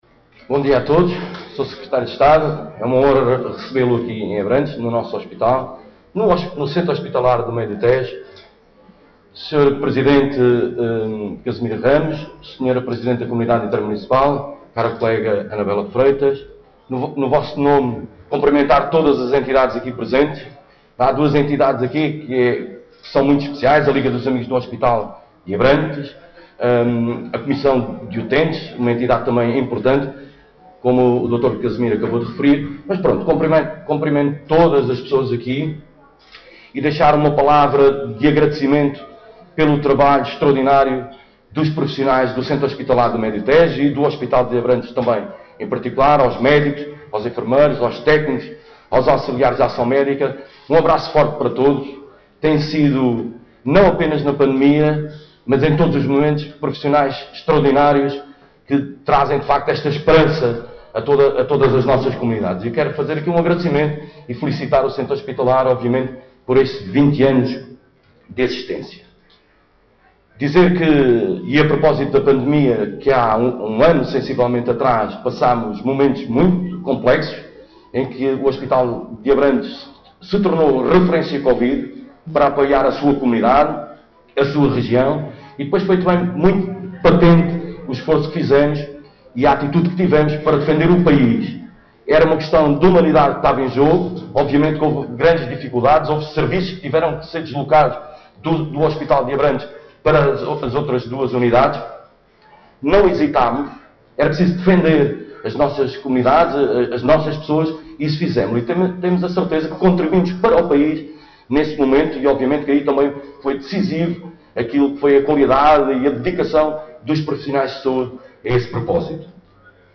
ÁUDIO | MANUEL JORGE VALAMATOS, PRESIDENTE CM ABRANTES: